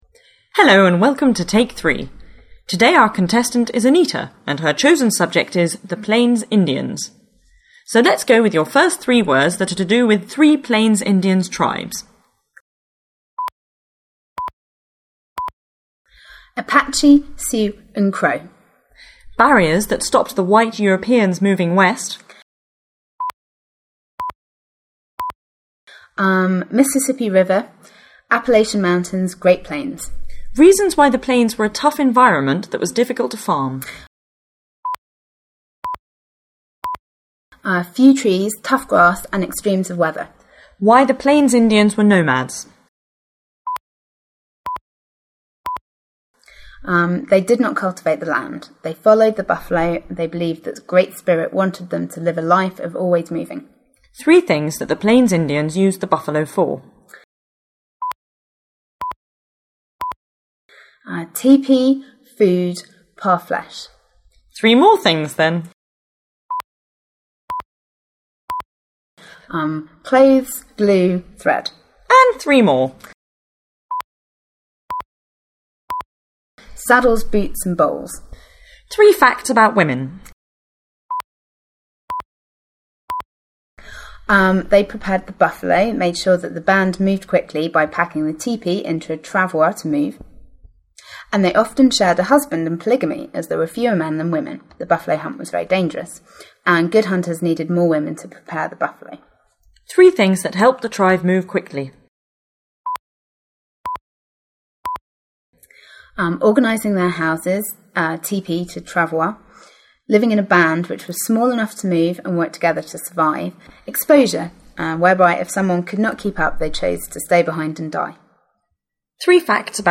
Plains Indians List Three - from Hodder; pausing the audio, give your answer before you listen to that of the contestant